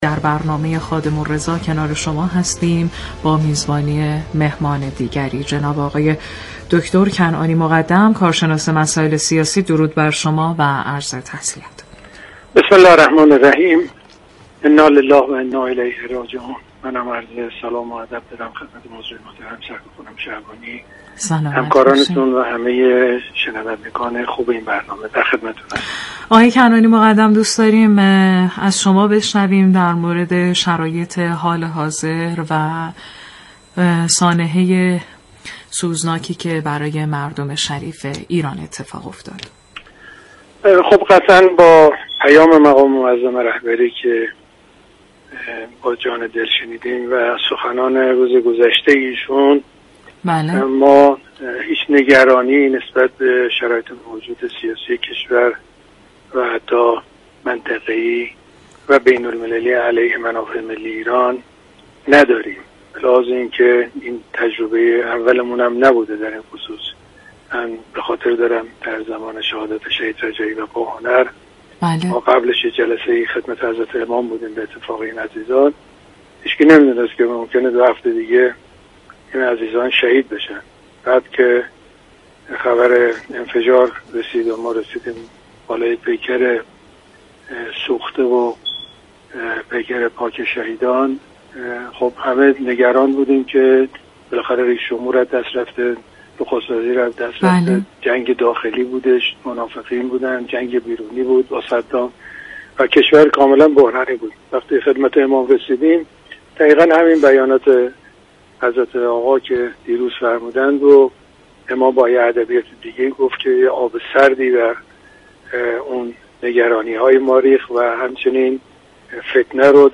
كارشناس مسائل سیاسی